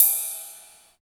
113 RIDE CYM.wav